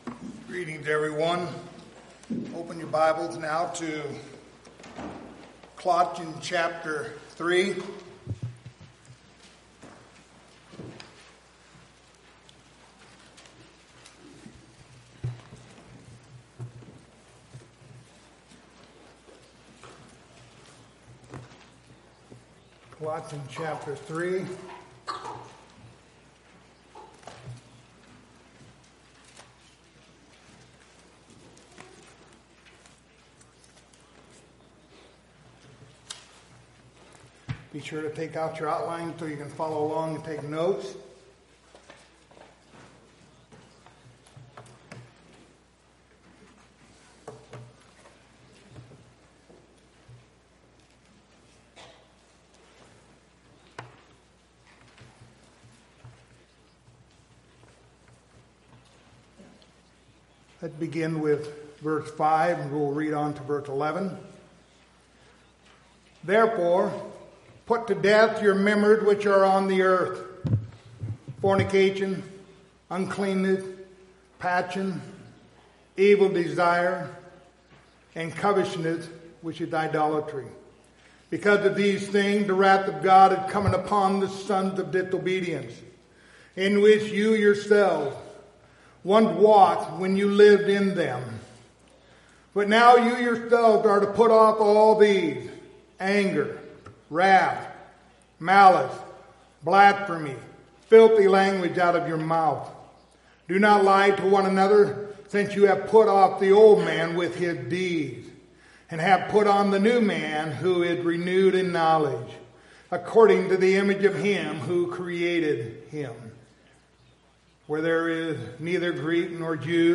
Passage: Colossians 3:5-7 Service Type: Sunday Morning